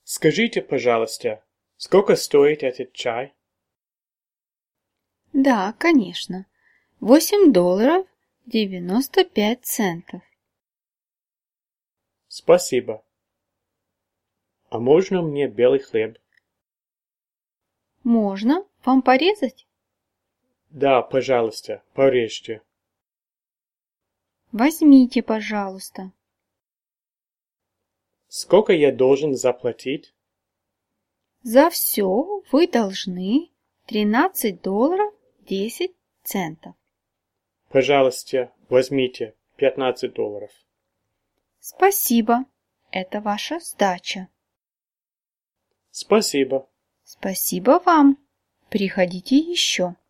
Many of the lessons on this site have a recorded audio component.